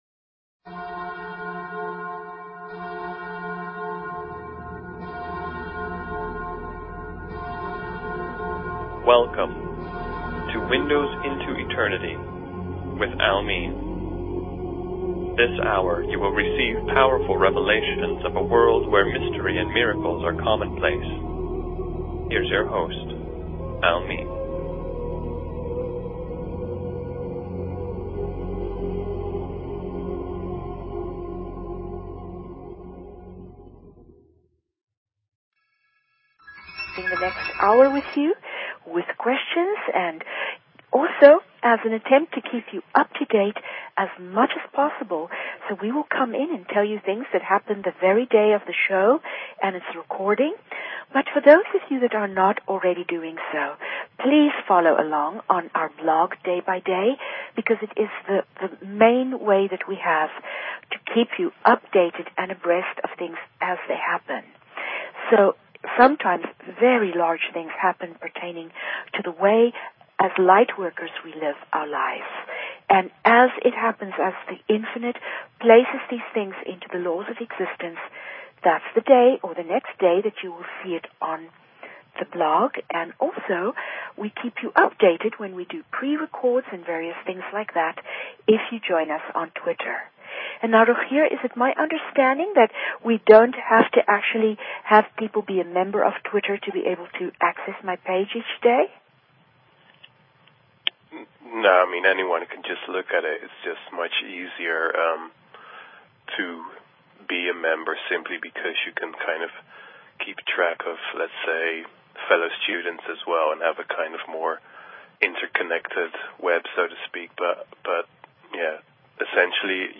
Subscribe Talk Show